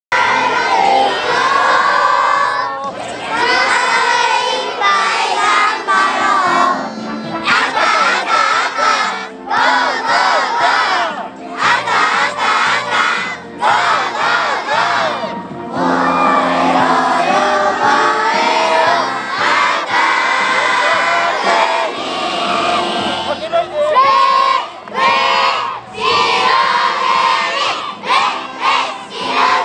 今日はラジオ体操の並び方と応援合戦を練習しました。
運動会の歌　赤組も白組も力いっぱい大きな声で歌うことができました。